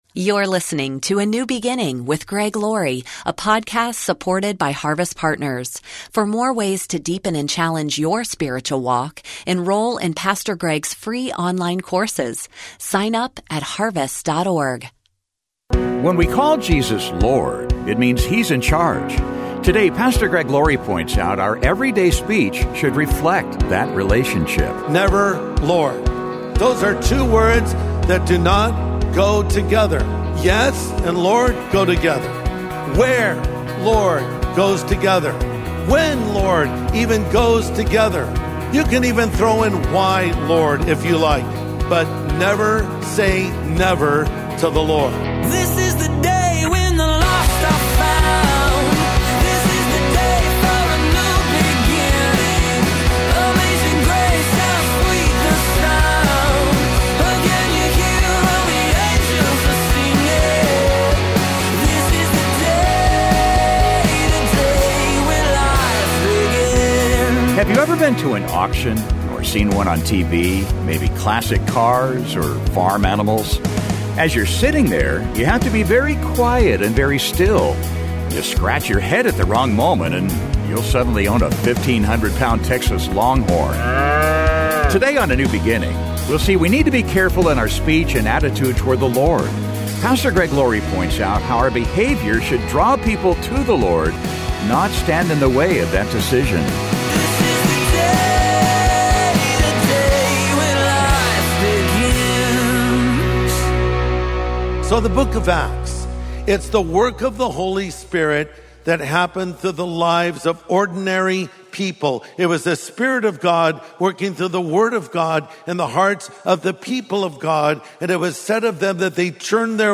Today on A NEW BEGINNING, we'll see we need to be careful in our speech and attitude toward the Lord. Pastor Greg Laurie points out how our behavior should draw people to the Lord, not stand in the way of that decision.